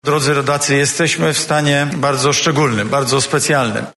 Mówił dzisiaj (11.03) premier Mateusz Morawiecki.